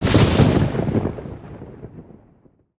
hit_2.ogg